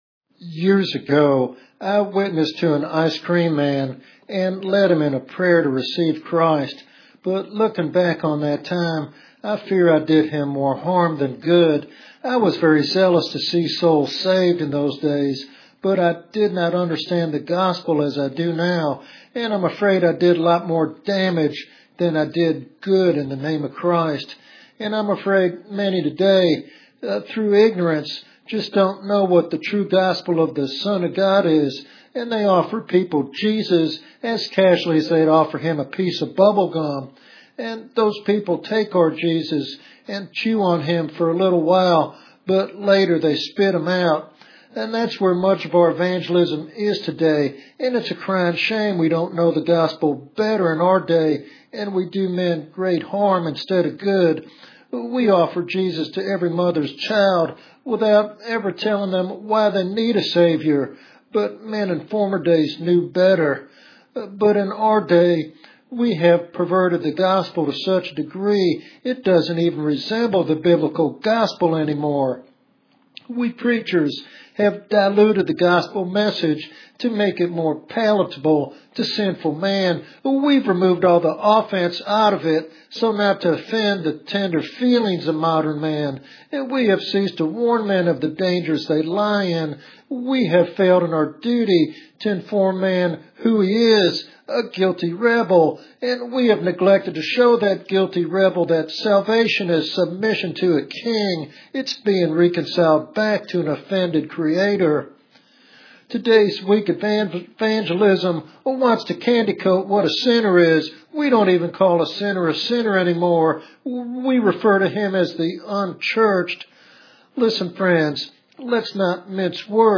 This sermon serves as a sobering call to both believers and preachers to faithfully proclaim the full counsel of God.